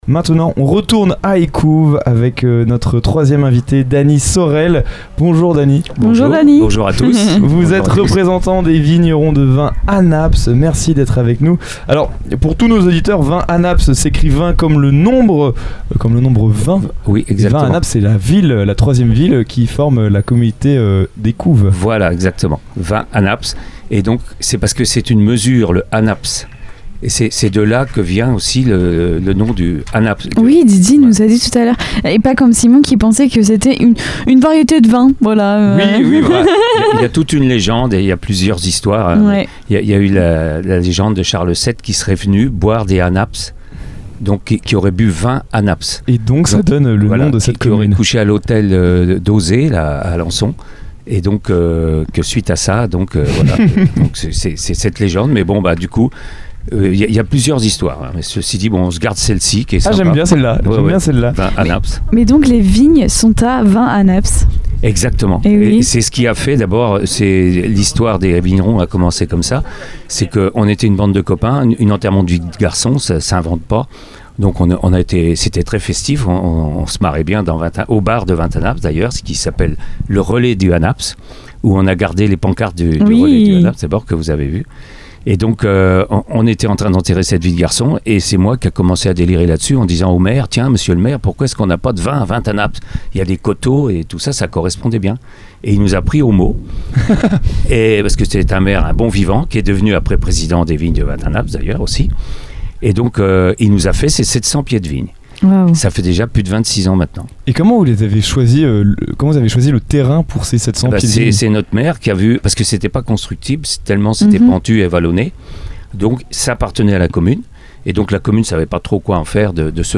Lors du MysteryMachine Summer Tour, notre studiomobile s'est arrêté dans la commune d'Écouves, non loin d'Alençon, pour découvrir les secrets et histoires de ce village.